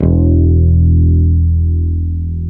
Index of /90_sSampleCDs/Roland L-CD701/BS _E.Bass 5/BS _Dark Basses